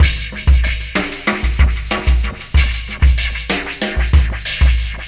Pulsar Beat 22.wav